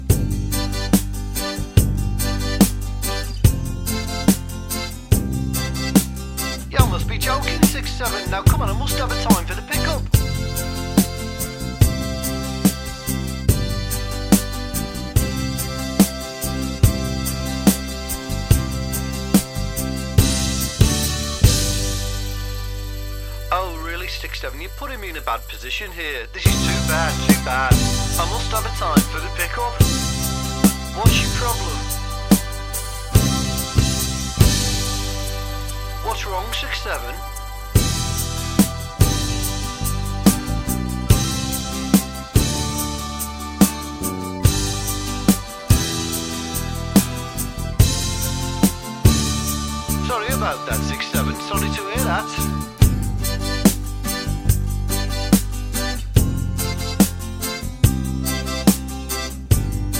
no Backing Vocals Comedy/Novelty 3:18 Buy £1.50